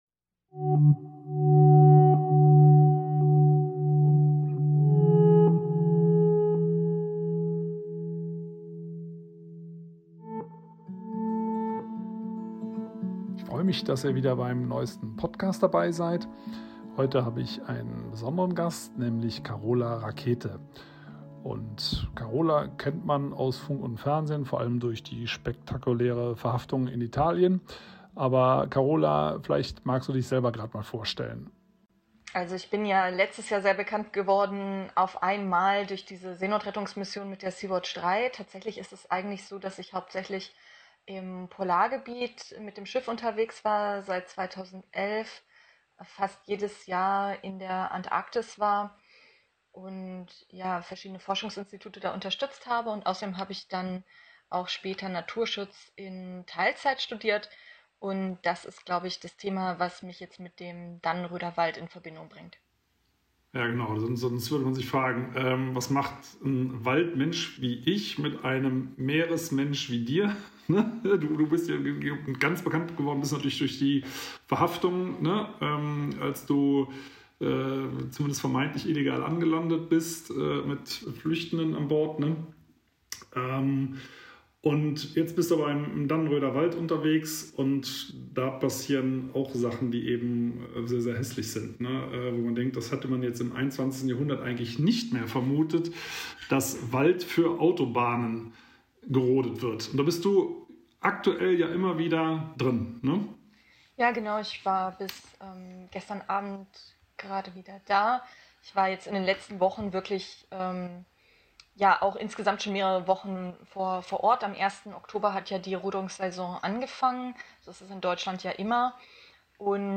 Peter Wohlleben spricht mit Kapitänin und Naturschutzökologin Carola Rackete über den Dannenröder Wald, warum eine Verkehrswende nötig ist und wie weit man für den Klimaschutz gehen darf.